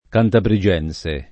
vai all'elenco alfabetico delle voci ingrandisci il carattere 100% rimpicciolisci il carattere stampa invia tramite posta elettronica codividi su Facebook cantabrigense [ kantabri J$ n S e ] (alla lat. cantabrigiense [ id. ]) etn.